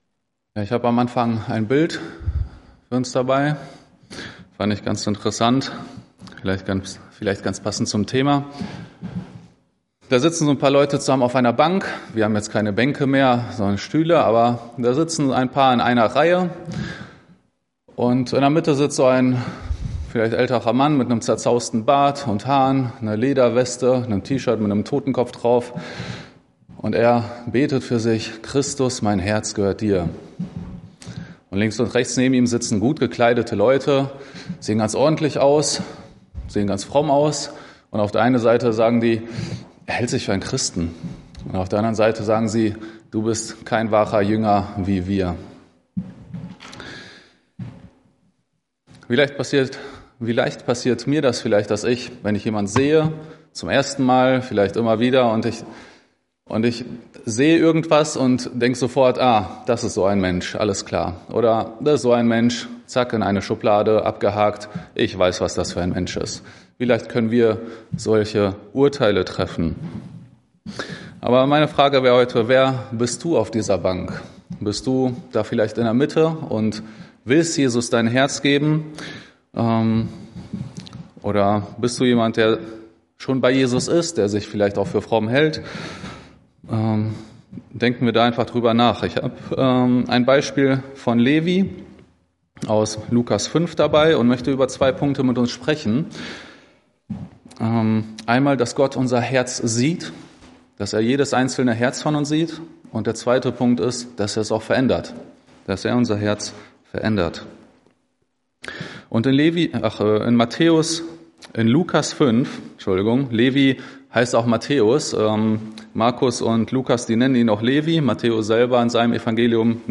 Prediger